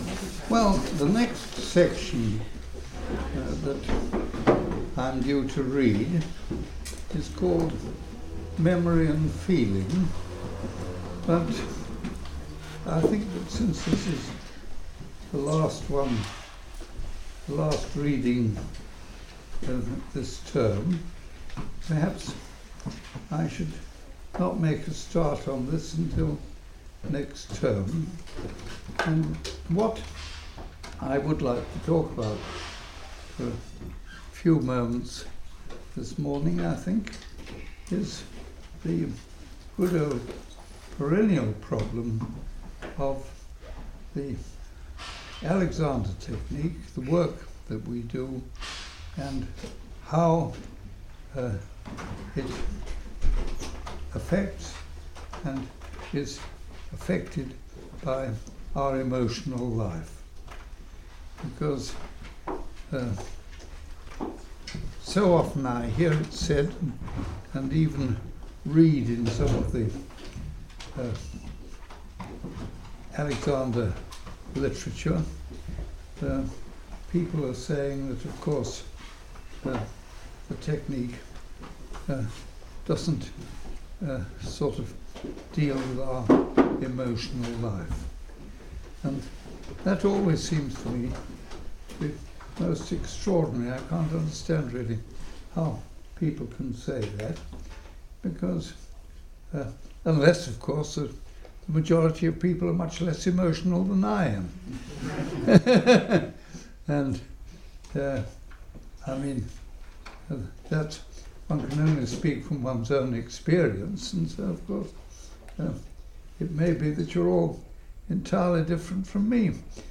An audio recording of a lecture